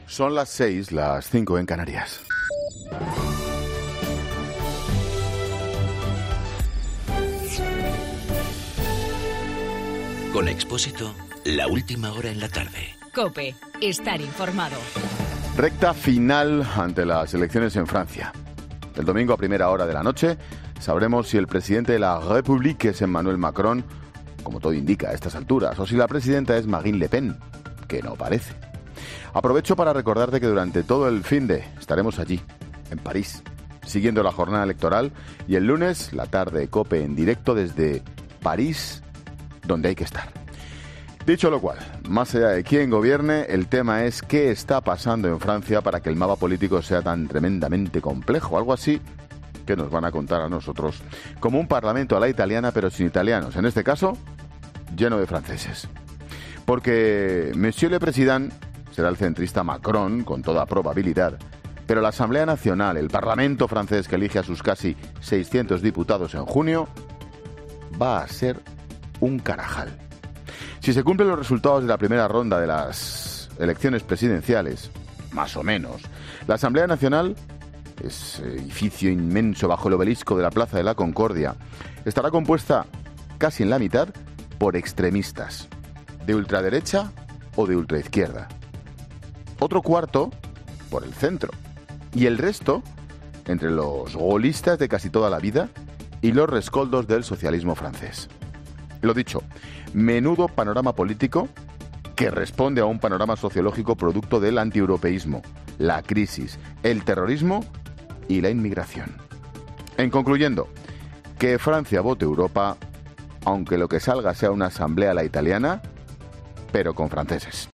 AUDIO: Monólogo 18 h.